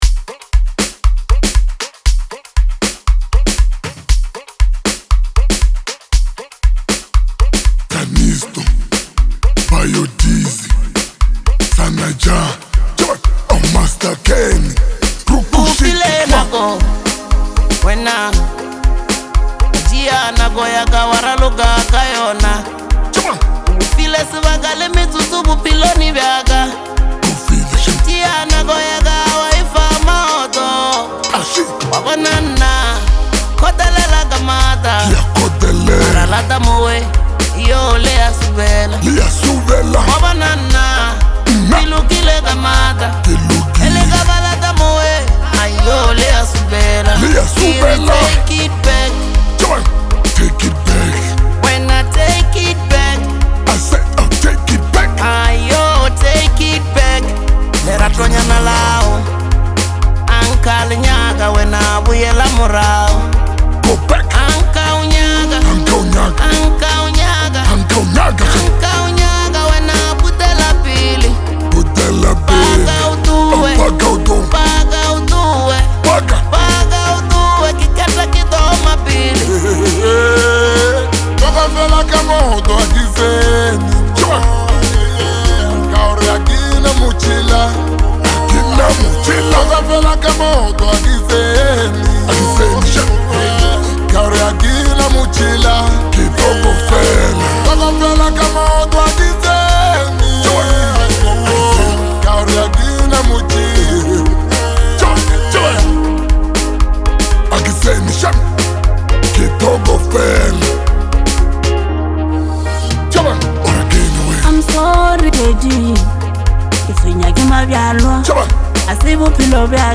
explosive energy,sharp creativity